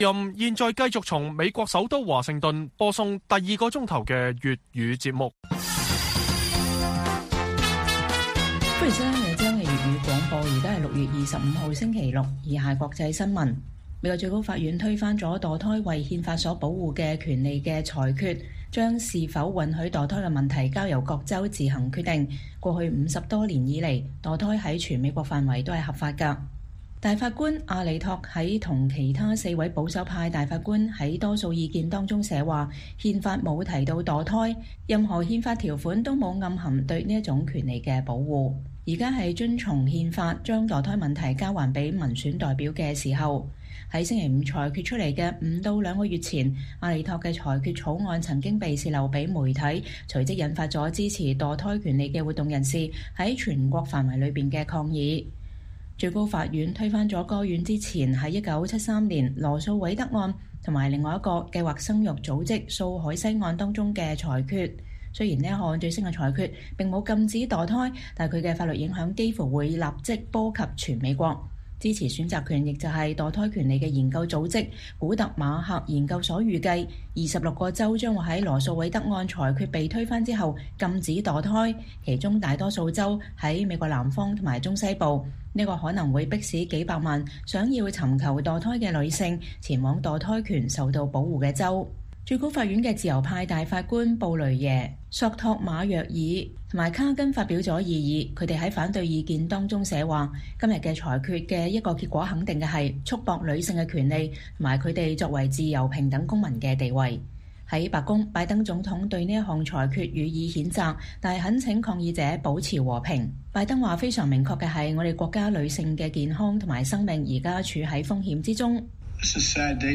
粵語新聞 晚上10-11點: 習近平將赴港參加香港歸還中國25週年活動